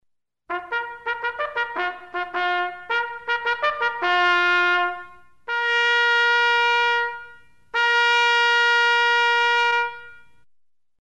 Звуки горна
Звук средневекового горна (некоторые путают с трубой)